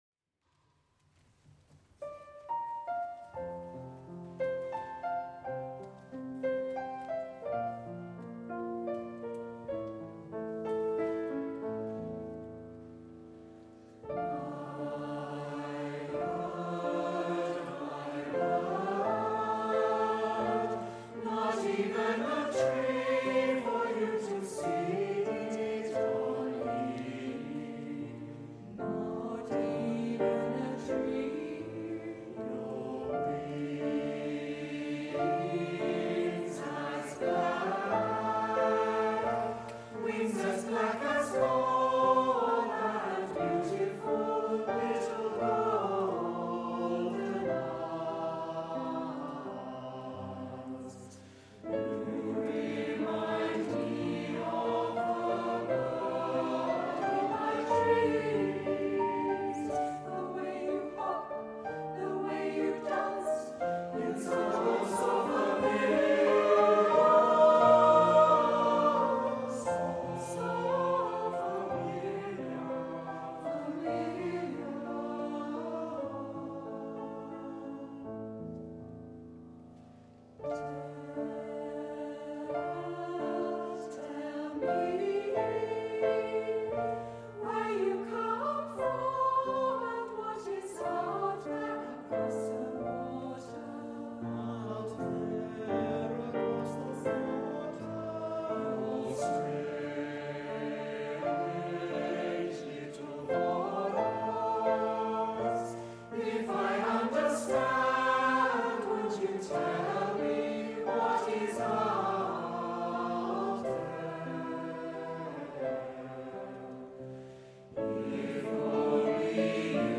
This version was adapted for mixed voices.
A beautiful, lyric song which your choir will love to sing!
SATB with piano live recording